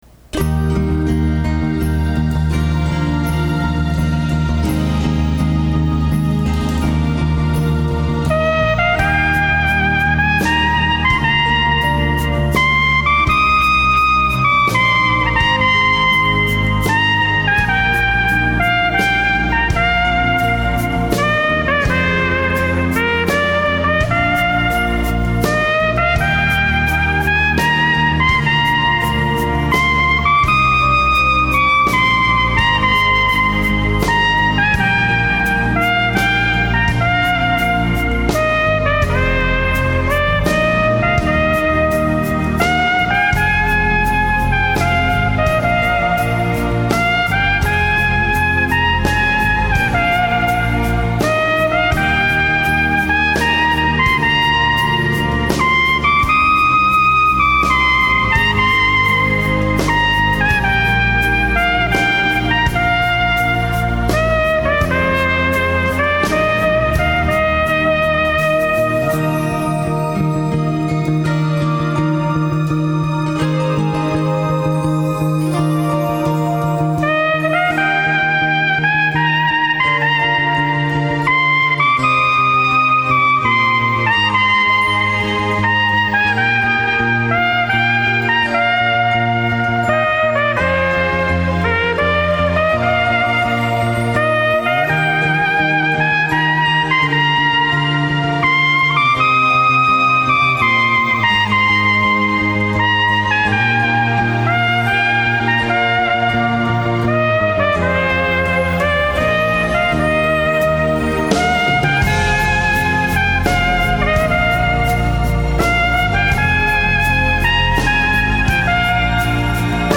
クリスマスの曲ばかり全24曲をピッコロトランペットで吹いて録音したものです。
(昔の)僕の音　（ピッコロトランペット）